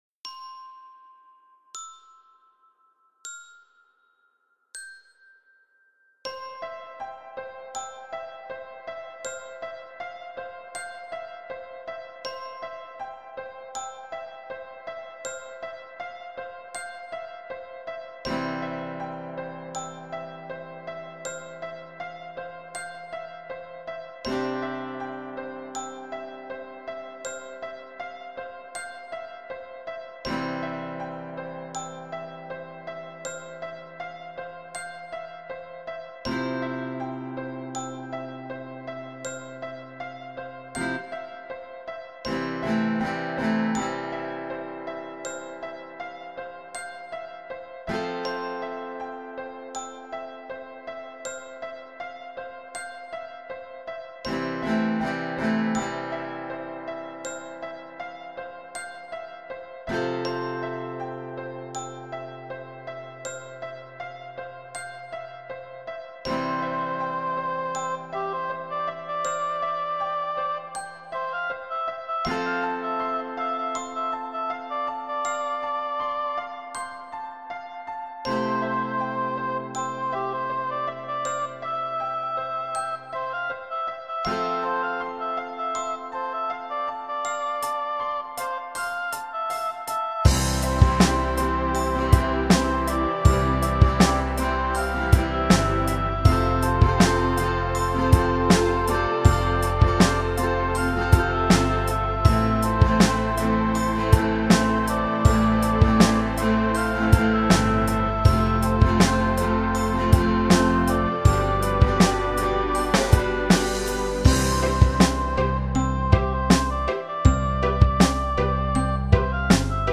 a power ballad (I guess) arrangement